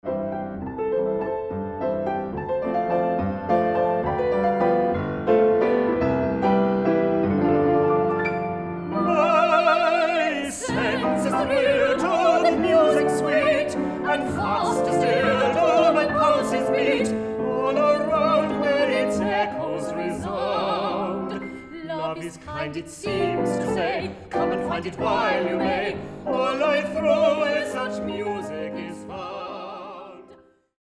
A Viennese whirl of glorious melodies, glamorous gowns and glittering ballrooms.